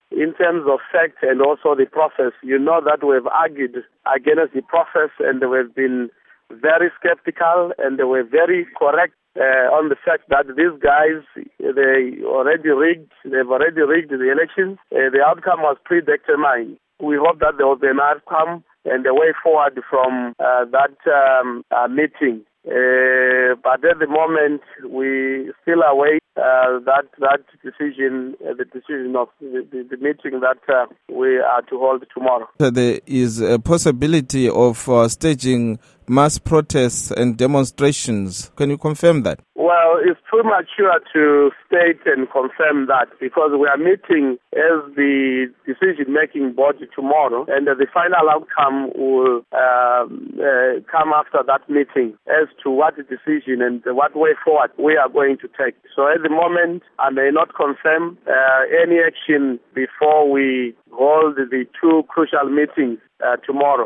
Interview With Lovemore Moyo